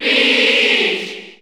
File:Peach Cheer Spanish NTSC SSB4 SSBU.ogg